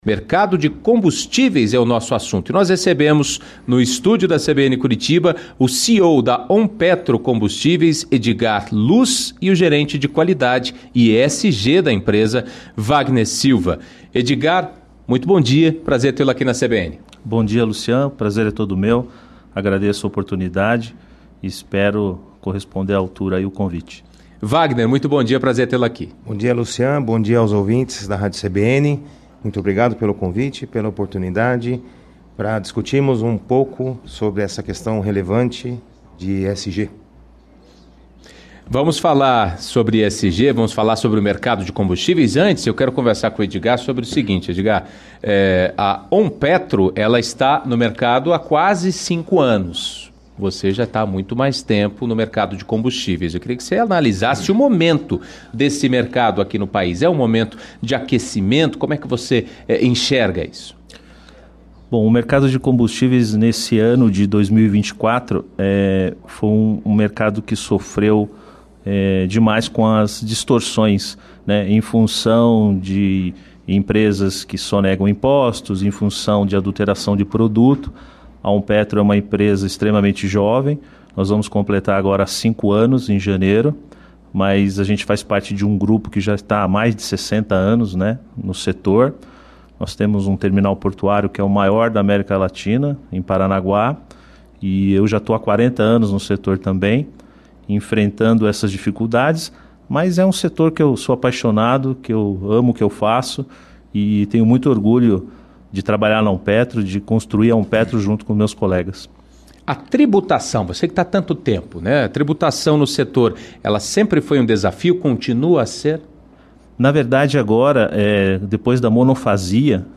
Confira um trecho da entrevista em vídeo